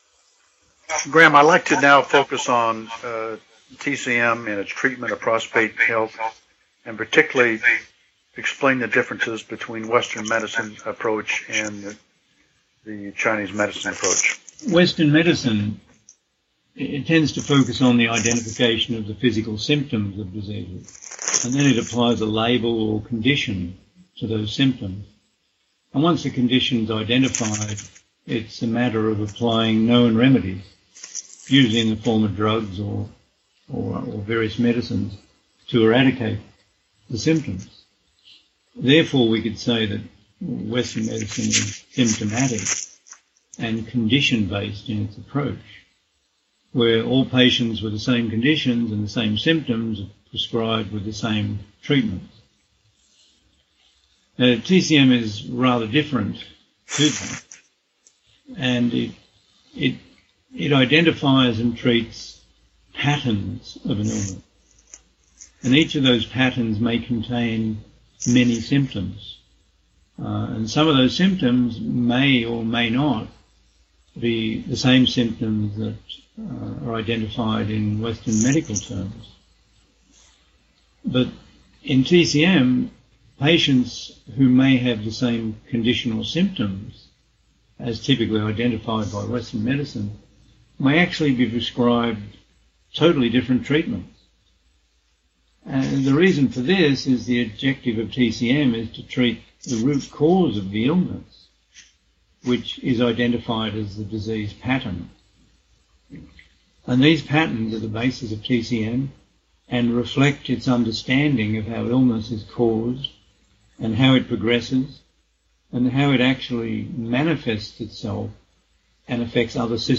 Interviews – 27 Most Important Health Questions